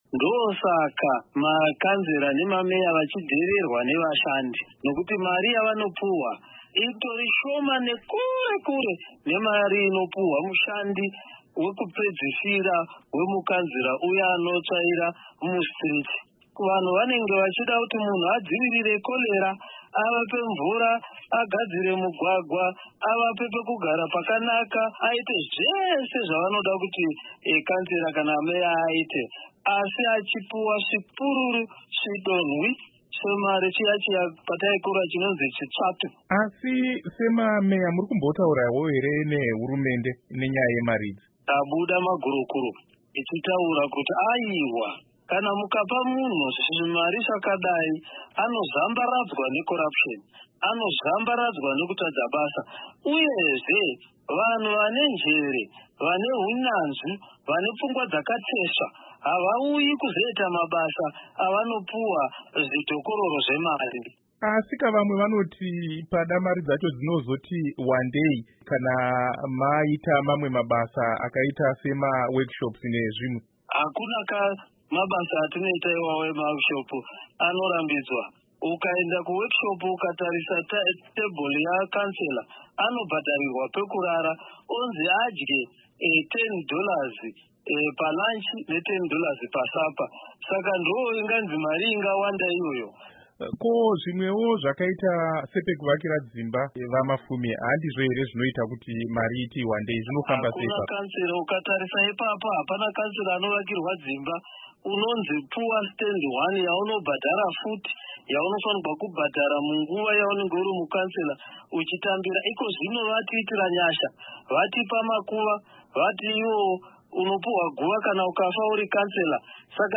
Hurukuro naVaJacob Mafume